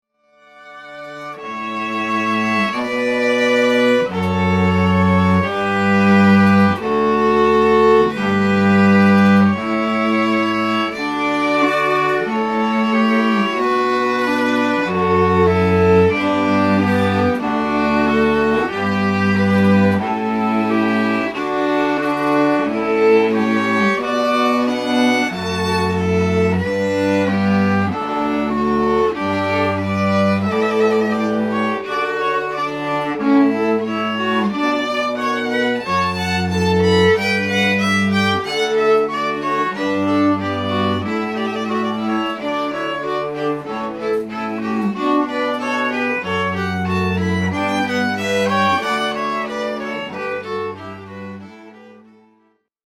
Listen | Camerata Bendigo String Quartet